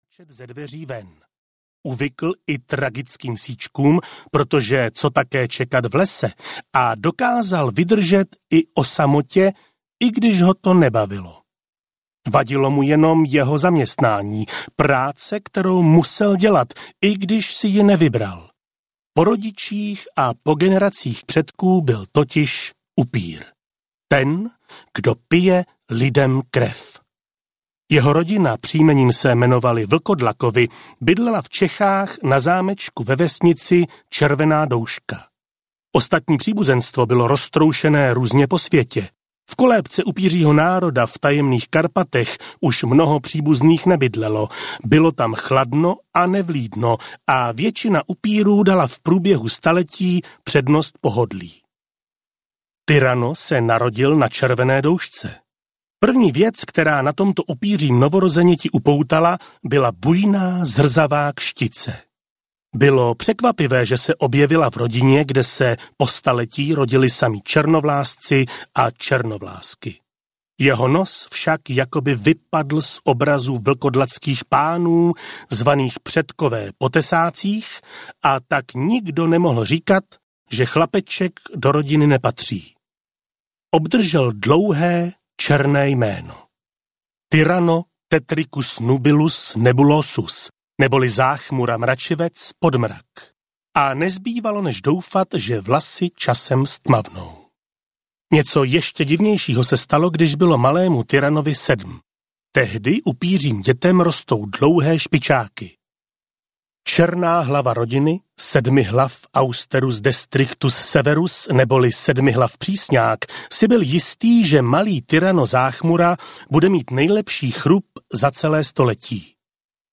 Tyrano - Dobrodružství českého upíra audiokniha
Ukázka z knihy